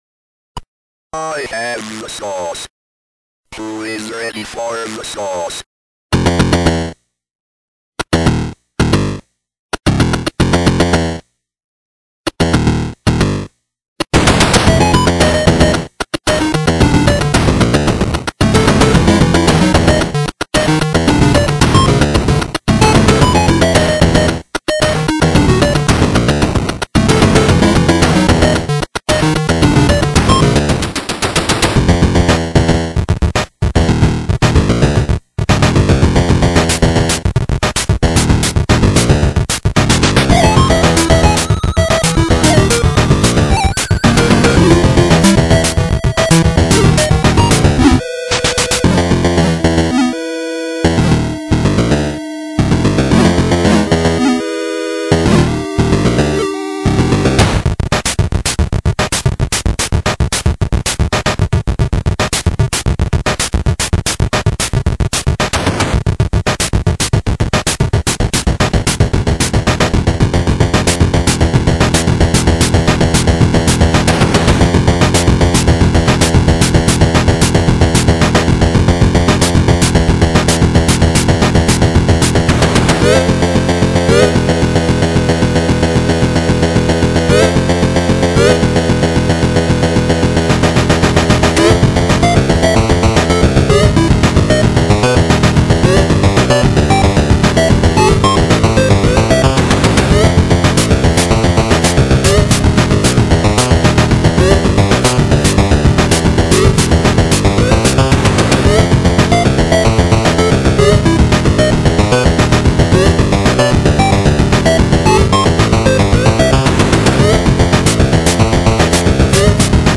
BPM112
Audio QualityCut From Video
CHIPTUNE